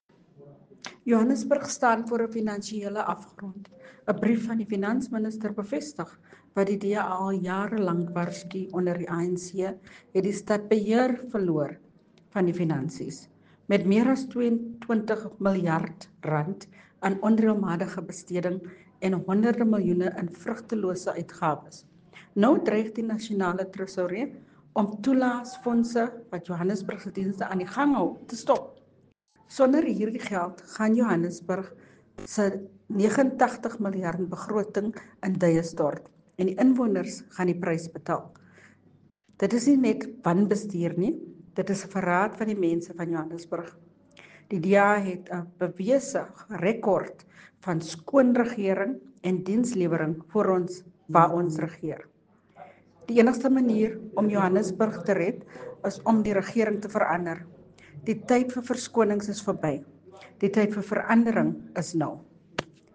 Issued by Cllr Belinda Kayser-Echeozonjoku – DA Johannesburg Caucus Leader
Note to Editors: Please find the letter here, and English and Afrikaans soundbites